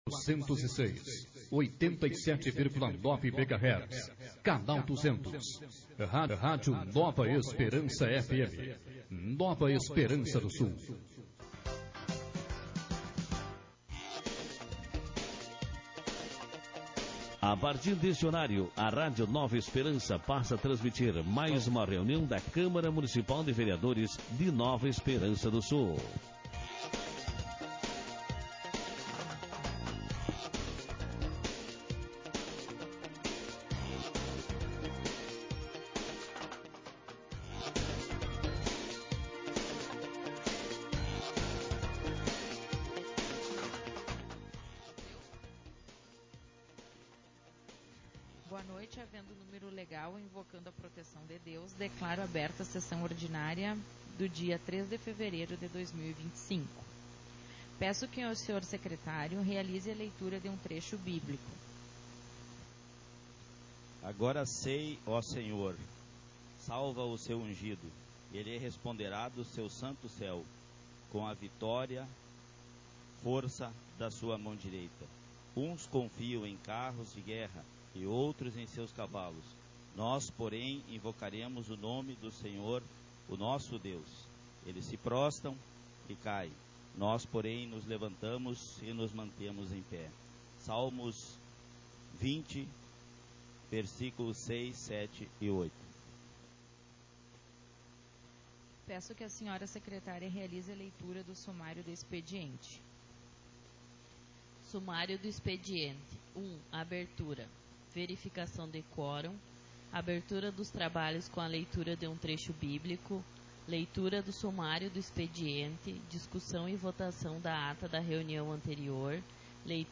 Sessões Plenárias 2025